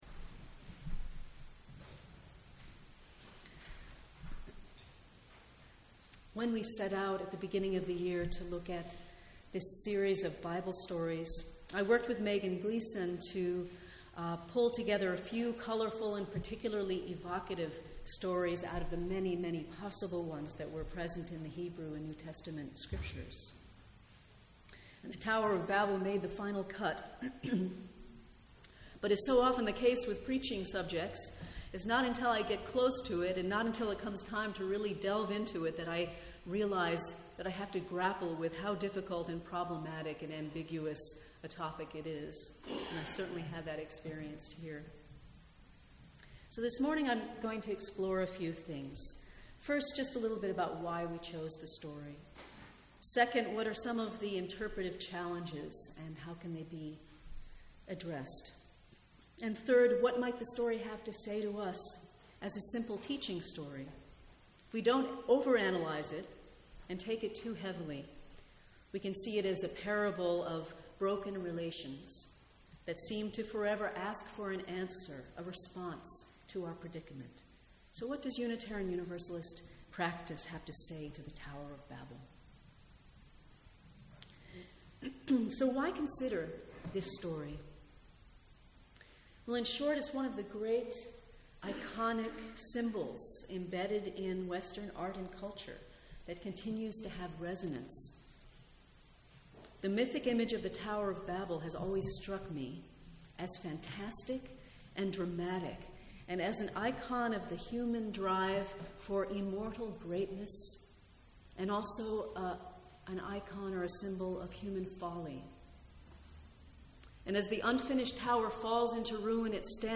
Tower of Babel sermon